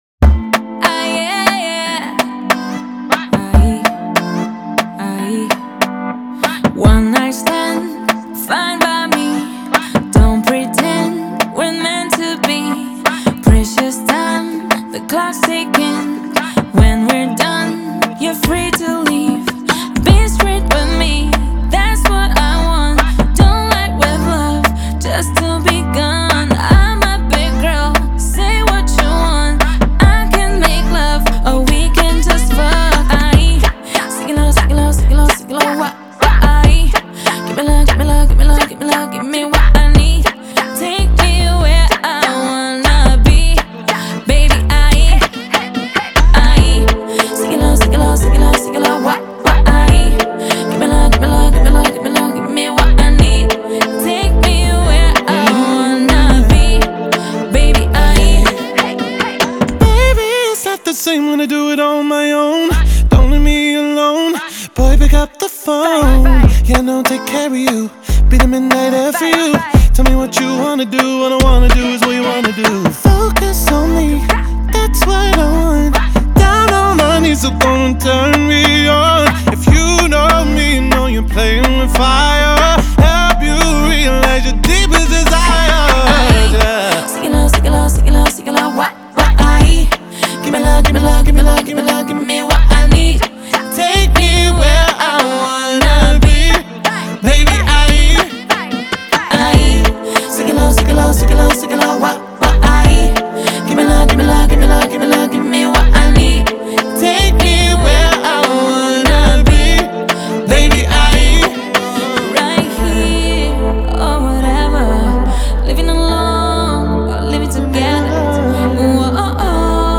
• Жанр: Pop, Hip-Hop